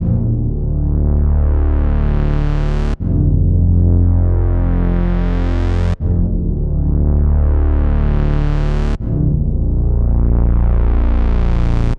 Index of /DESN275/loops/Loop Set - Aerosol - Ambient Synth Loops - F and Dm/Loops
VacantExpanse_80_Dm_Bass.wav